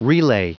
Prononciation du mot relay en anglais (fichier audio)
Prononciation du mot : relay
relay.wav